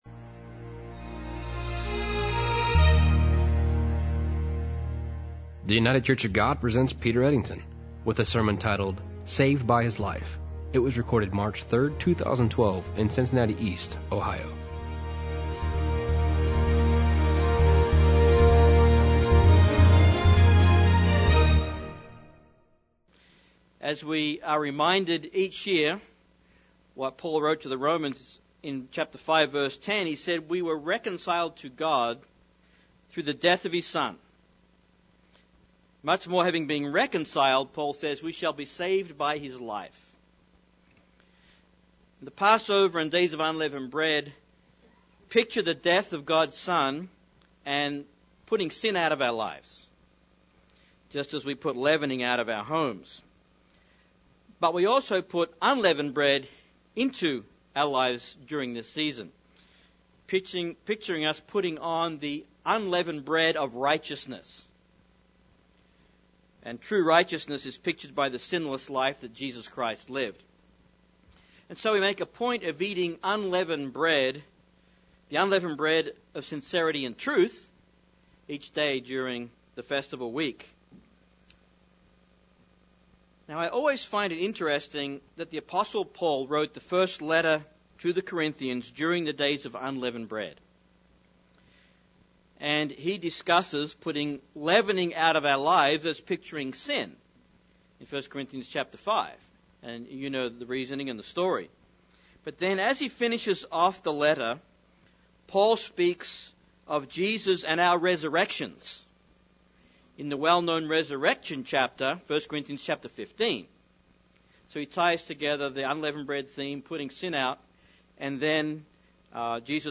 In this sermon we will take a look at the question, was Jesus really resurrected?